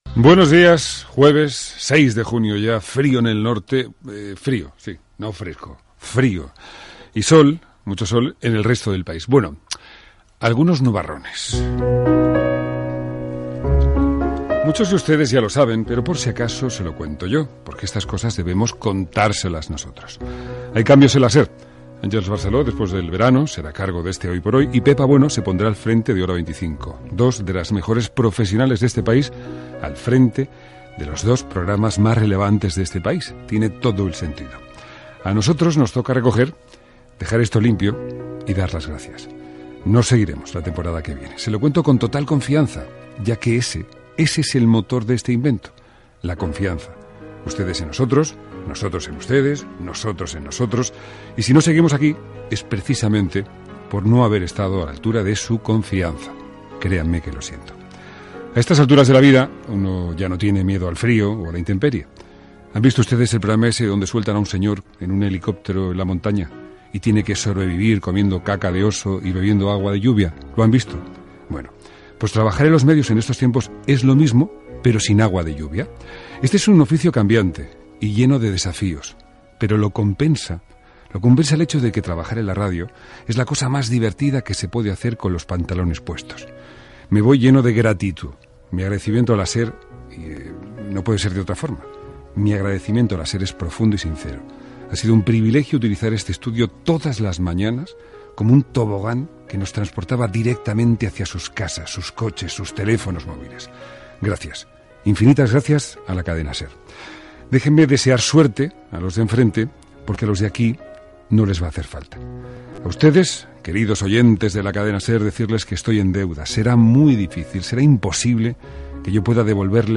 Toni Garrido s'acomiada com a presentador del programa, n'explica els motius i agraïment i demanda a l'audiència
Info-entreteniment